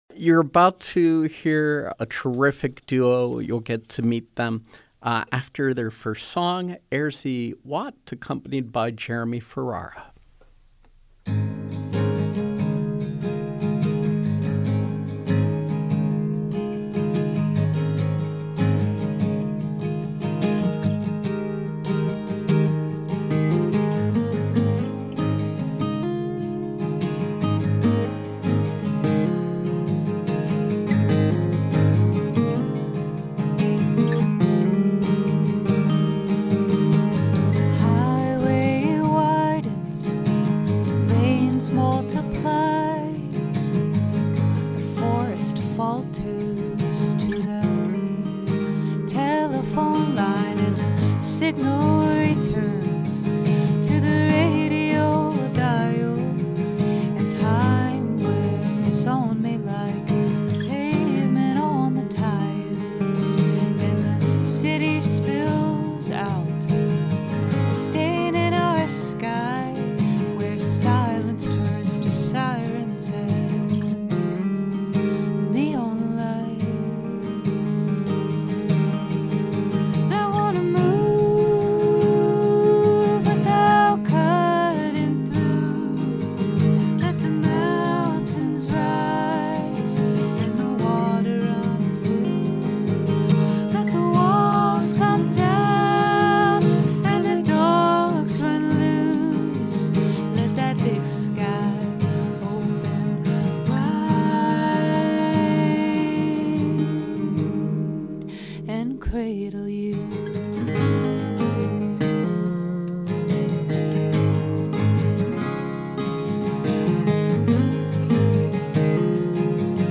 Live music and conversation with singer-songwriter